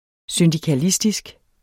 Udtale [ søndikaˈlisdisg ]